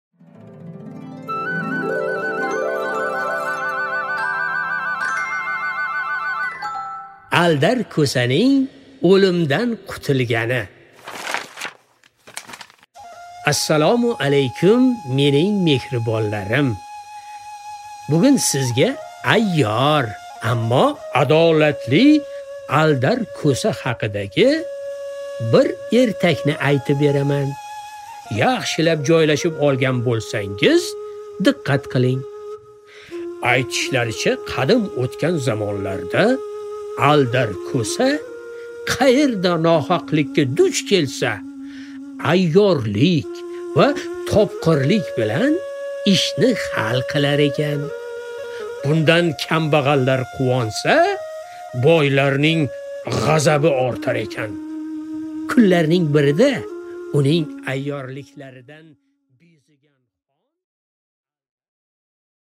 Аудиокнига Aldar Ko'saning o'limdan qutulgani | Библиотека аудиокниг